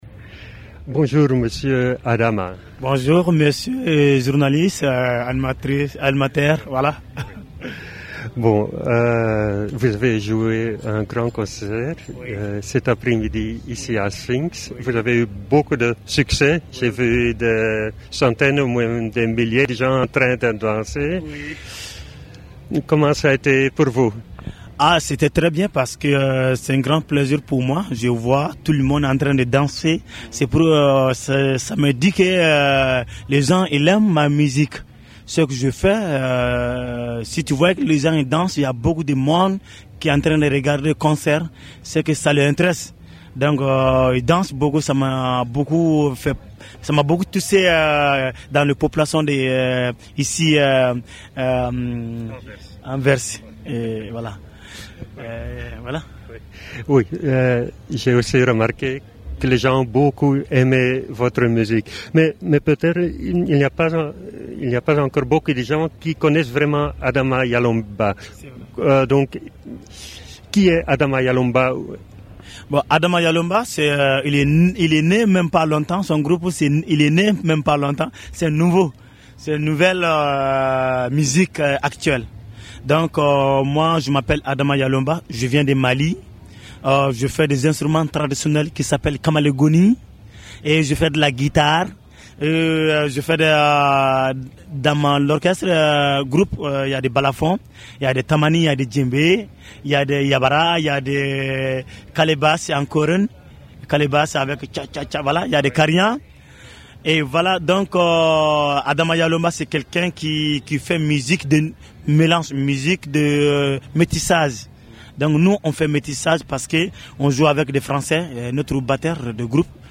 [Sfinks] Radio Centraal interviewt